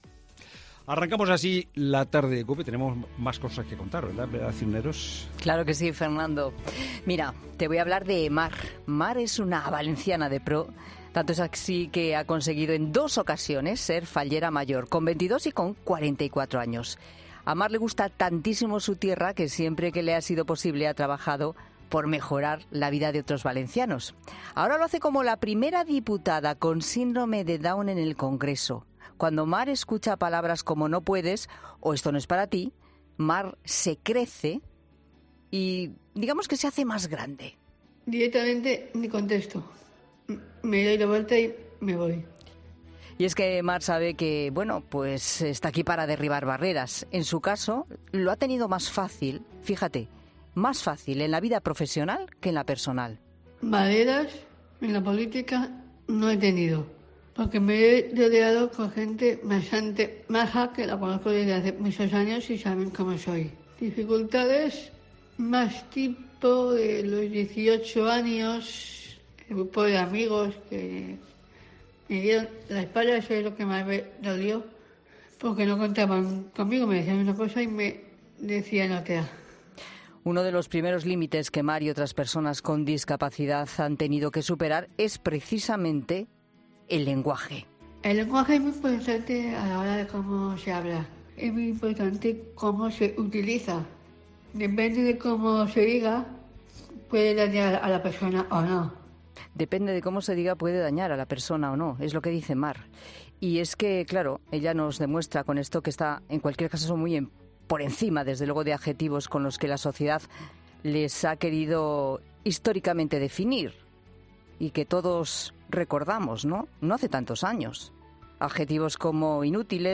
en el estudio de 'La Tarde' de COPE